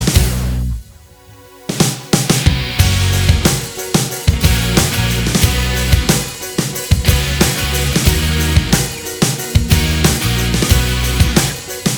Minus Clean Guitars Pop (2010s) 3:10 Buy £1.50